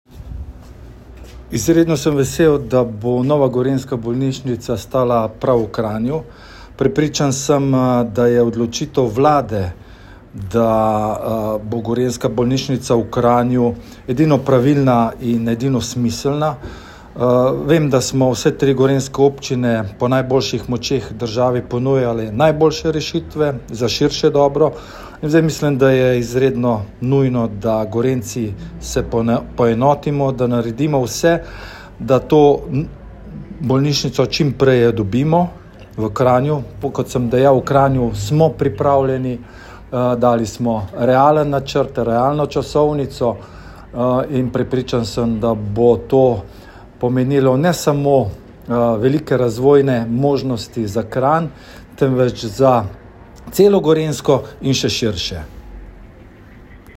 Tonski posnetek izjave župana Mestne občine Kranj Matjaža Rakovca